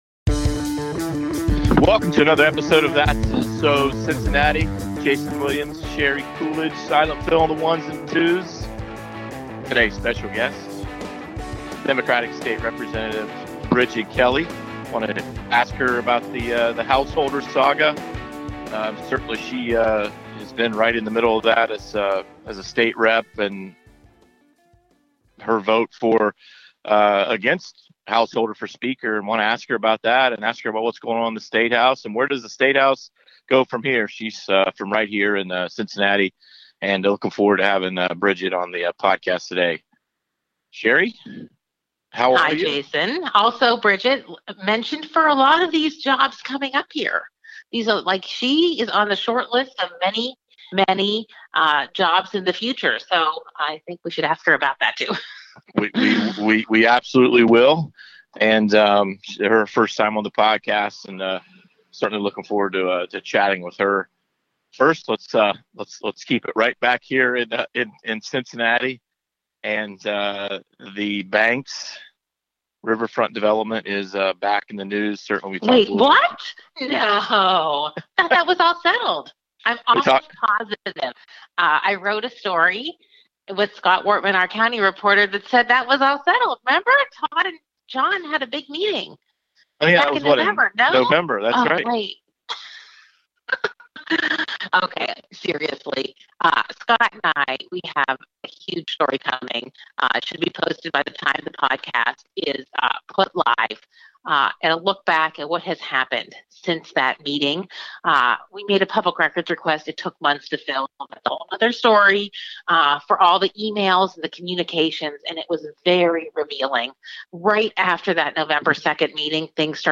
The Seitz interview begins at the 30:15 mark in the episode.